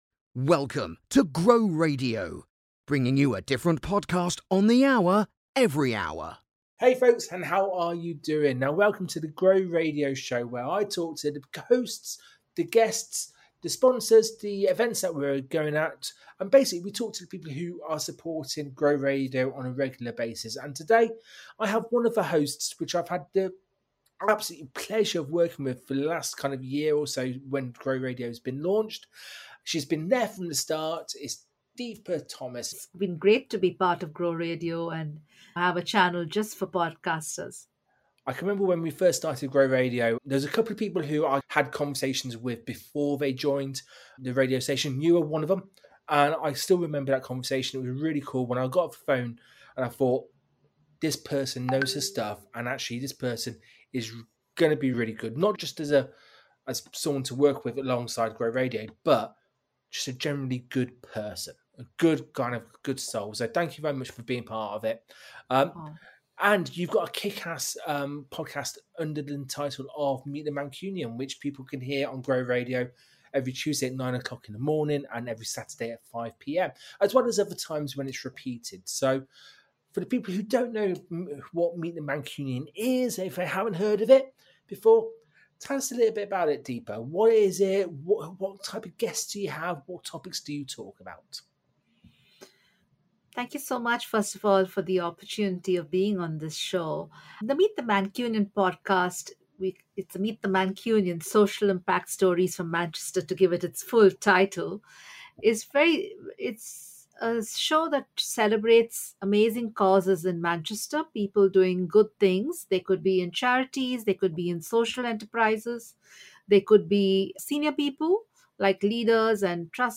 Welcome to episode eleven of The GROW Radio Show, your go-to podcast for insightful conversations with hosts, sponsors, event organisers, and community leaders associated with GROW Radio.